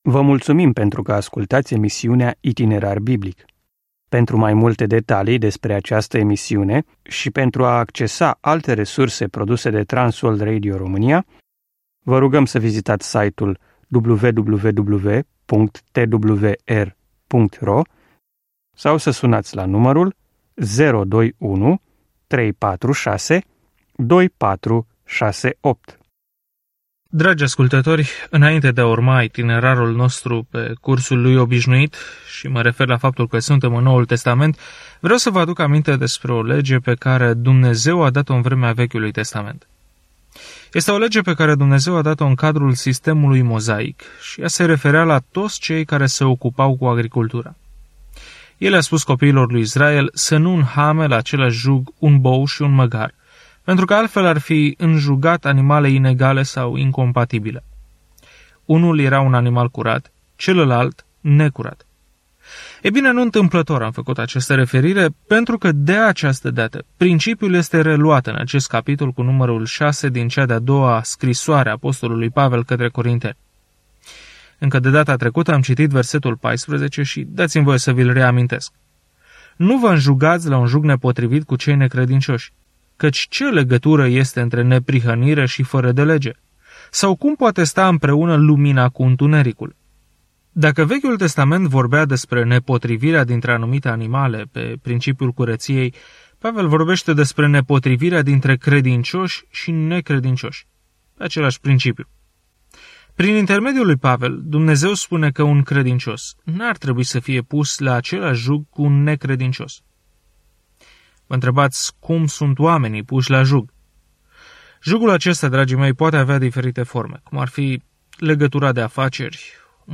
Călătoriți zilnic prin 2 Corinteni în timp ce ascultați studiul audio și citiți versete selectate din Cuvântul lui Dumnezeu.